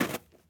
SnowSteps_01.wav